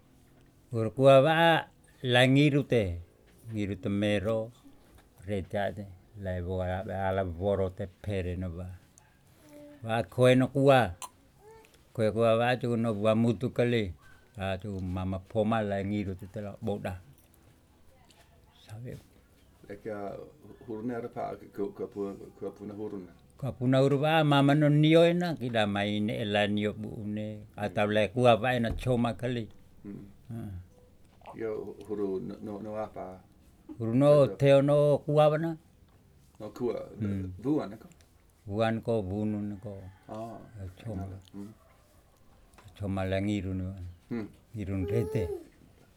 I recorded in the afternoon, with the AT2020 mic, sitting in the main, front room, my "studio". This is 'huru kua' (huru papaya). It is a sign/prohibition that is hung in fruit trees.
digital wav file recorded at 48 khz/24 bit
Palu'e, Flores, Nusa Tenggara Timur, Indonesia.